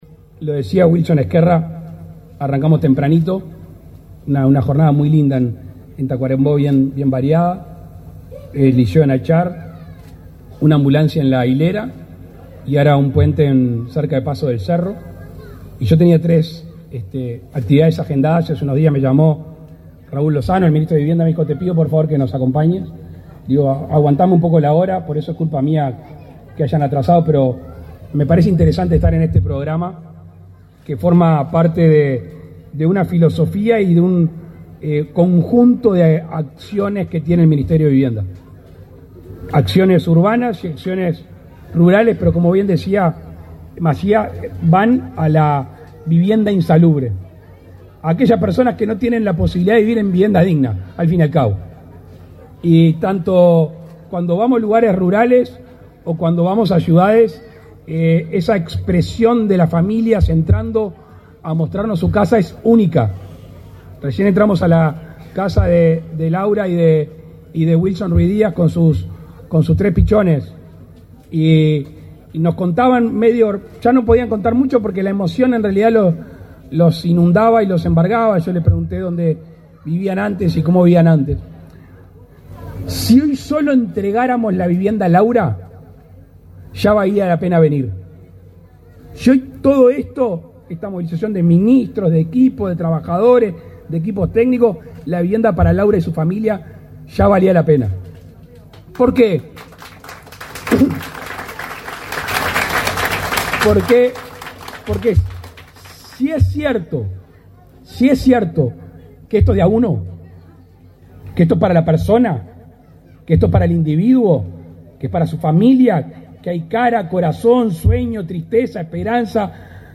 Palabras del presidente de la República, Luis Lacalle Pou
El presidente de la República, Luis Lacalle Pou, participó, este 2 de setiembre, en el acto de inauguración de 9 viviendas del grupo Arerungua, en la